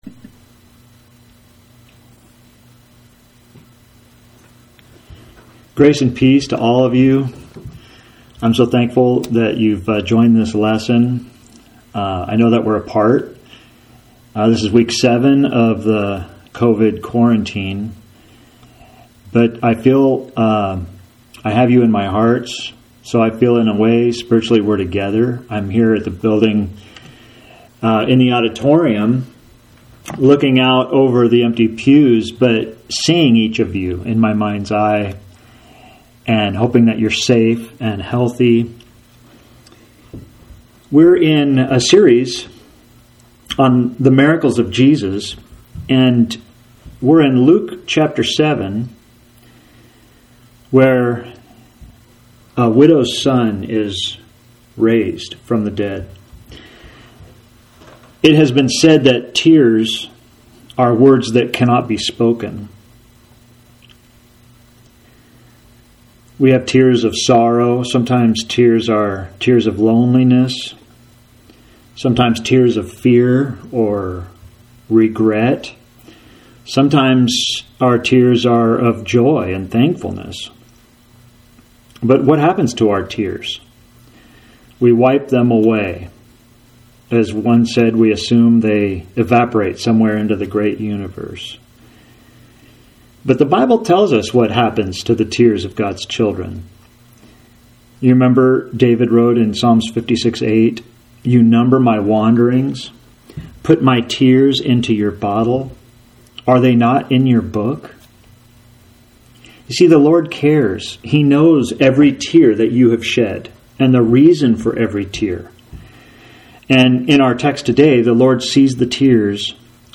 Sermon for Sunday May 10. Lesson 10 in a series on the miracles of Jesus.